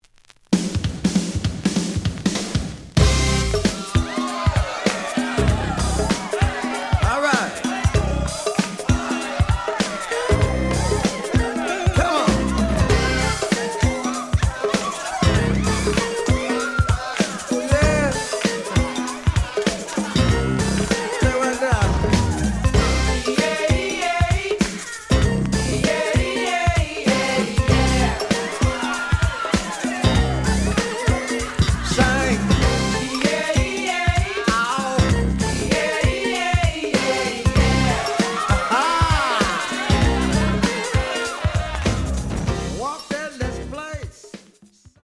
The audio sample is recorded from the actual item.
●Genre: Hip Hop / R&B
A side plays good.